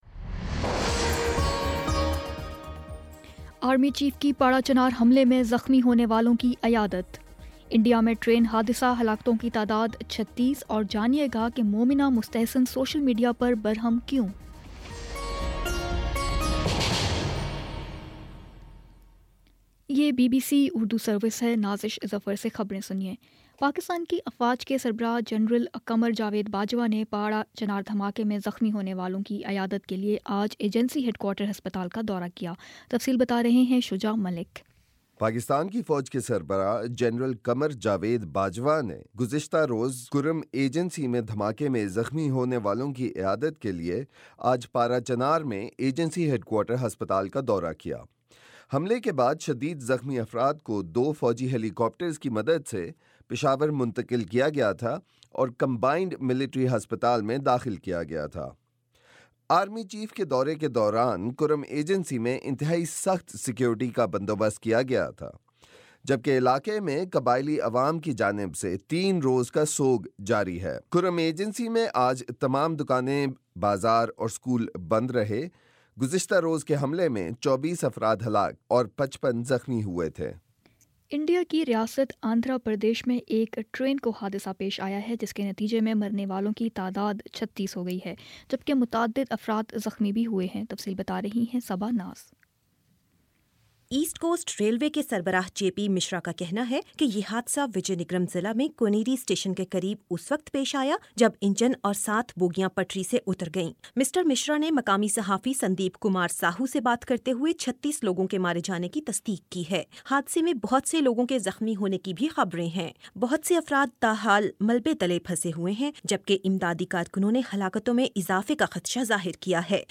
جنوری 22 : شام سات بجے کا نیوز بُلیٹن